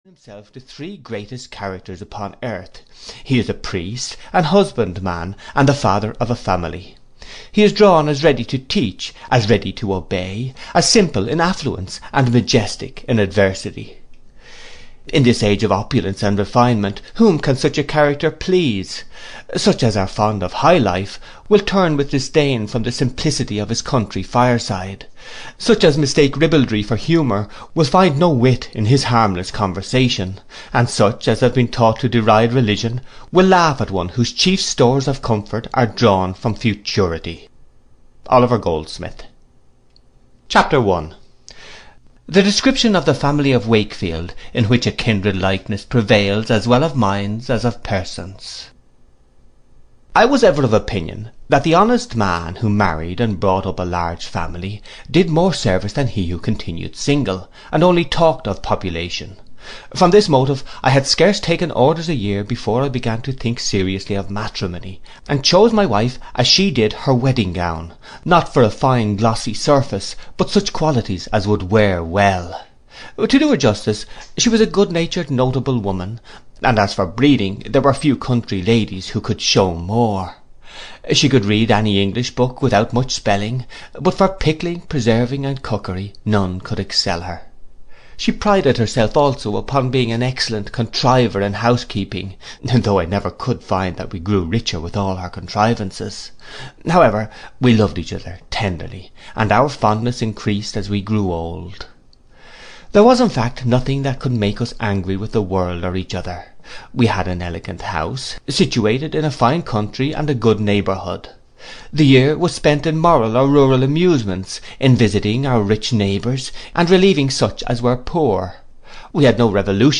The Vicar of Wakefield (EN) audiokniha
Ukázka z knihy